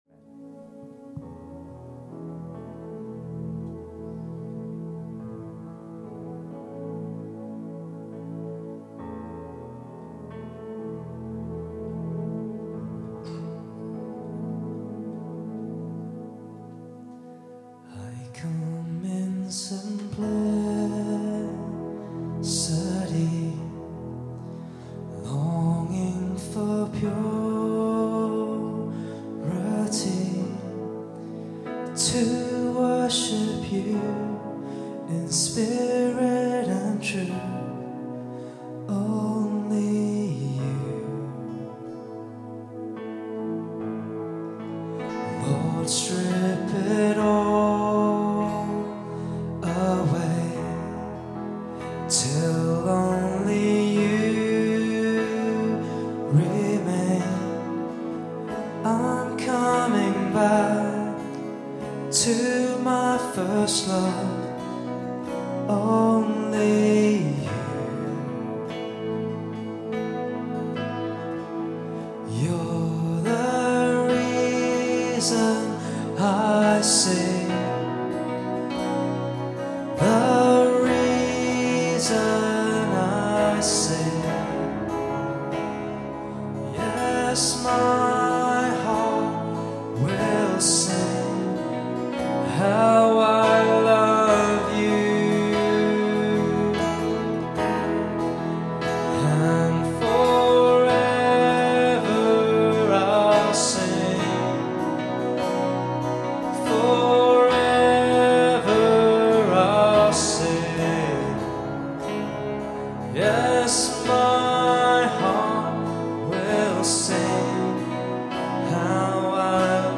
Worship August 10, 2015 – Birmingham Chinese Evangelical Church
Keys
Guitar
Vocals
Cajon